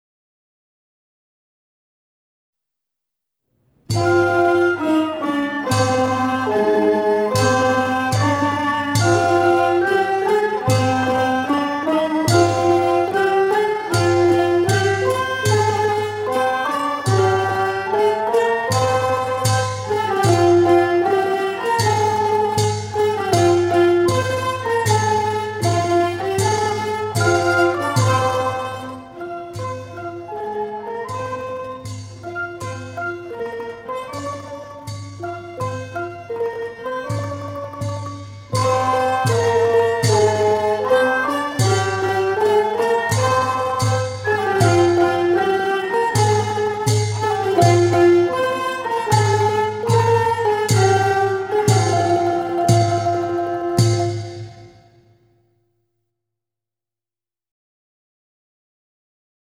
วงมโหรี